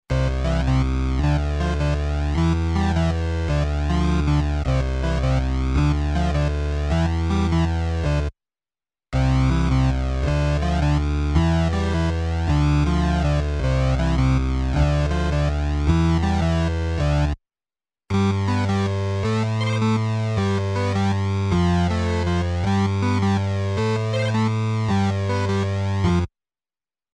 8 bit Gaming Musik
Tempo: langsam / Datum: 15.08.2019